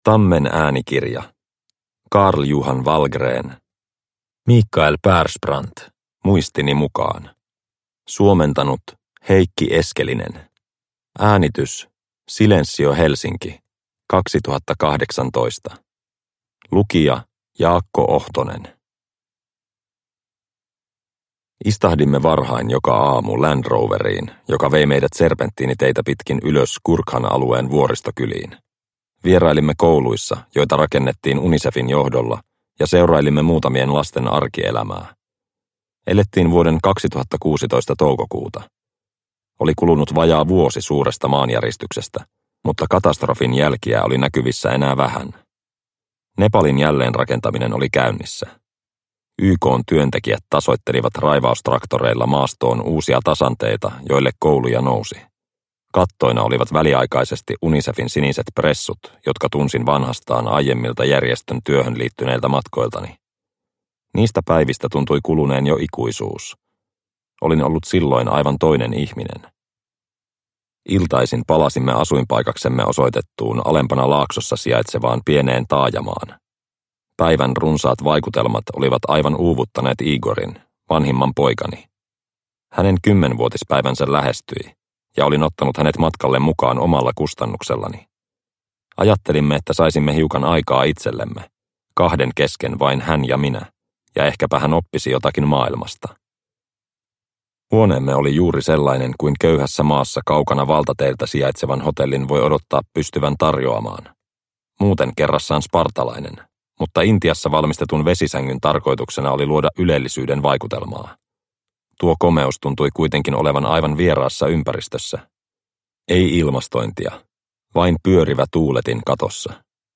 Mikael Persbrandt - Muistini mukaan – Ljudbok – Laddas ner